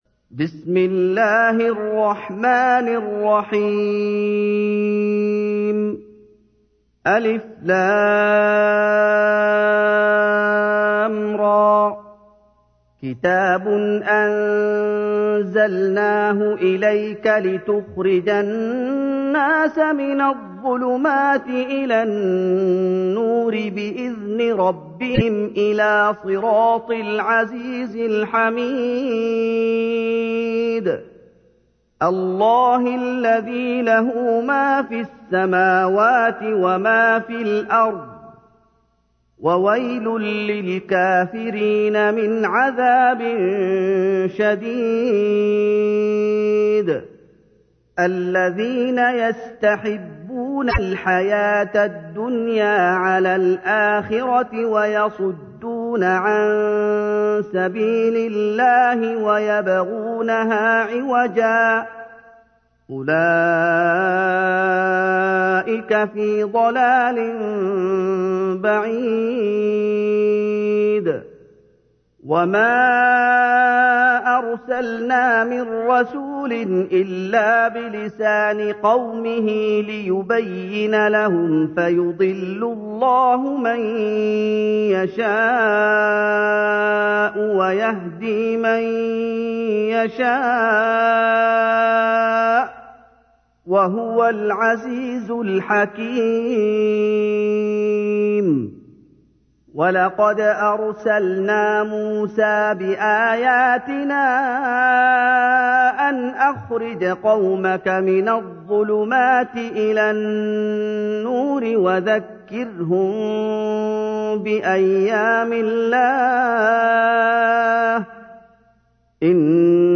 تحميل : 14. سورة إبراهيم / القارئ محمد أيوب / القرآن الكريم / موقع يا حسين